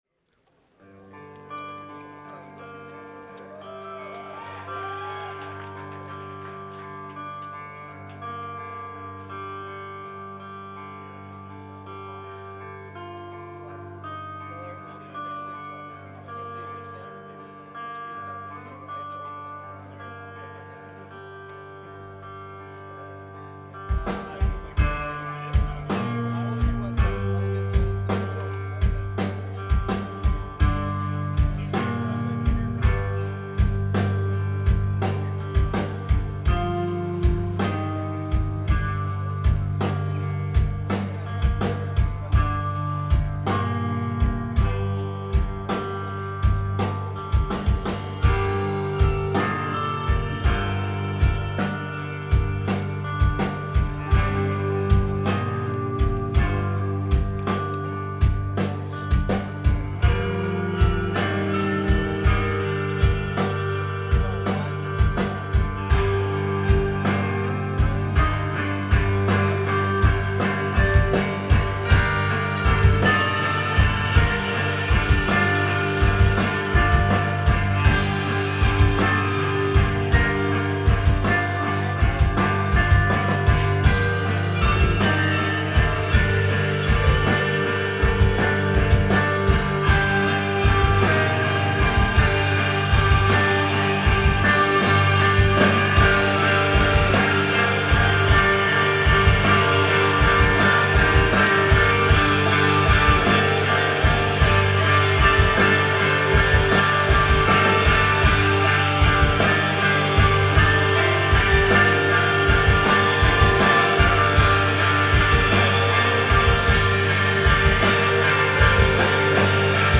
Live at Avalon
Boston, MA